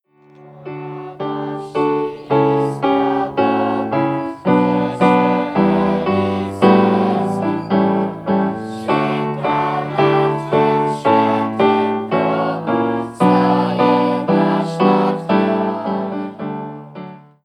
Punktualnie w południe unijny hymn wybrzmiał w wielu polskich miastach, a na maszt przed ełckim ratuszem wciągnięto unijną flagę.